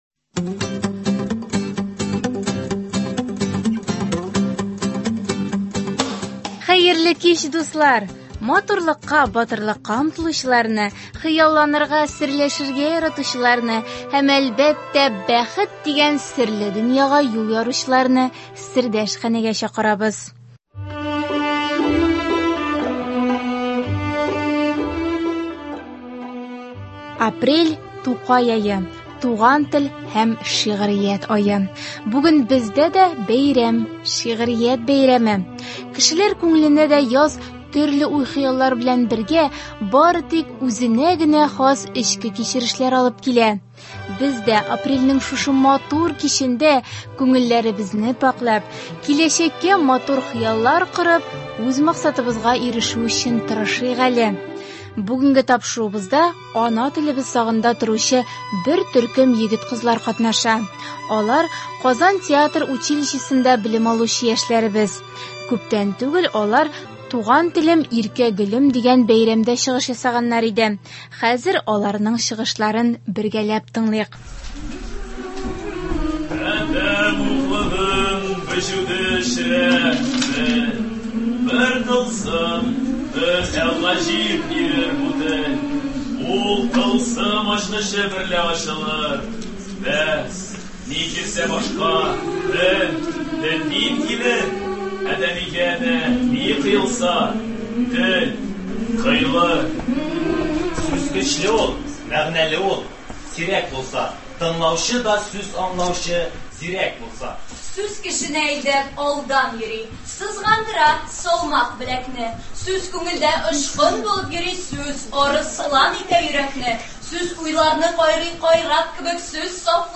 Апрель – Тукай ае, туган тел һәм шигърият ае. Бүгенге тапшыруыбызны да ана телебез сагында торучы бер төркем егет – кызлар катнашында туган телебезне зурлаган шигъри тәлгәшләрдән әзерләдек.